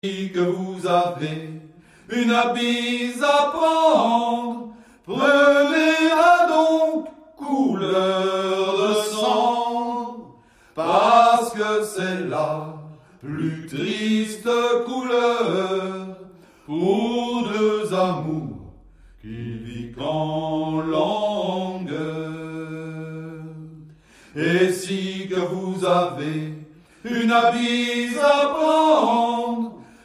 Musique : Traditionnel
Origine : Louisiane